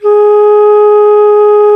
Index of /90_sSampleCDs/Roland LCDP04 Orchestral Winds/FLT_Alto Flute/FLT_A.Flt nv 3
FLT ALTO F09.wav